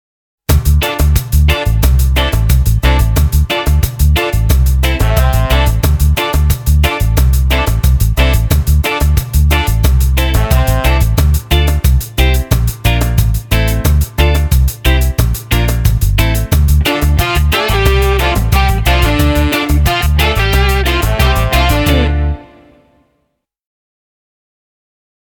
L’ABC de la guitare électrique pour débutants
Riffs, rythmiques, solos, arpèges, improvisation.